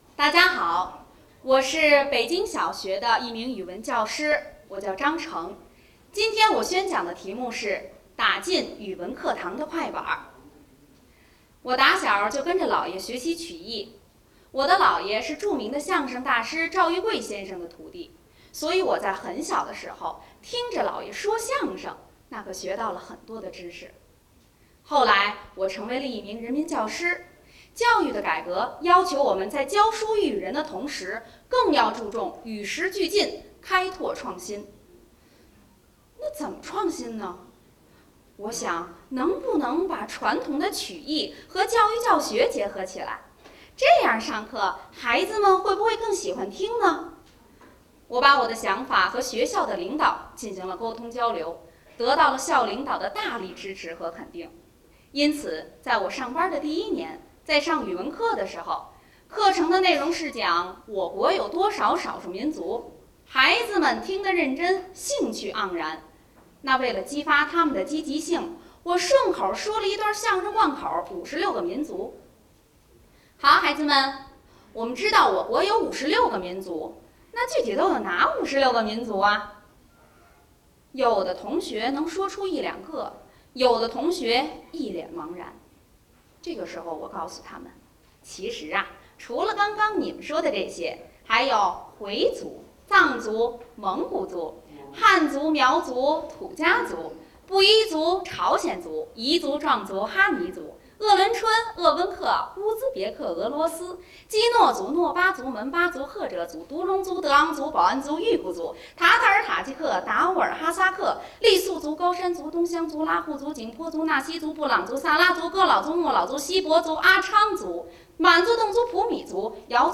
打进语文课堂的快板